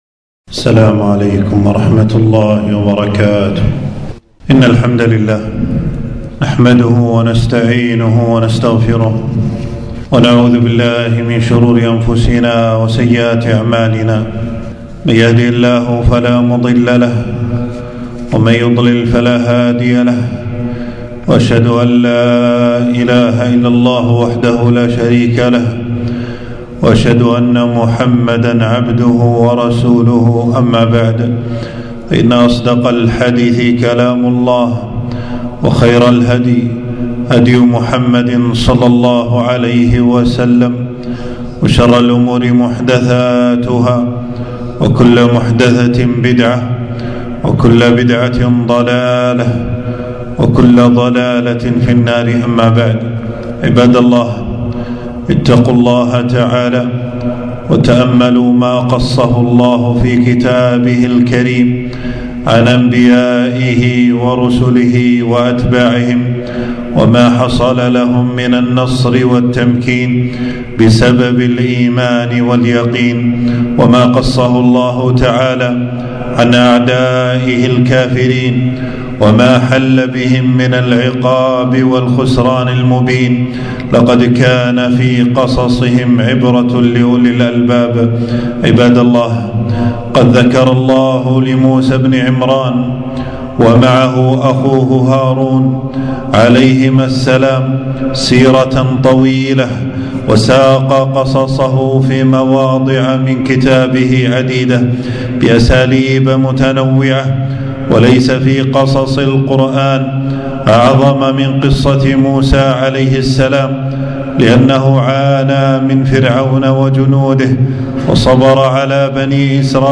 خطبة - قصة موسى عليه السلام وكيف أنجاه الله من فرعون